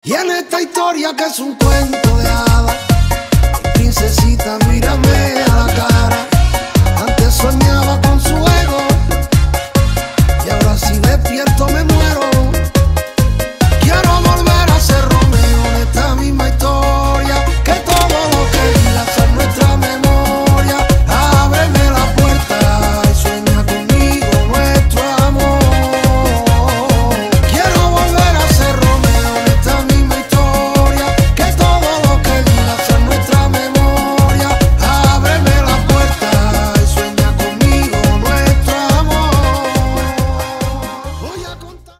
Electrónica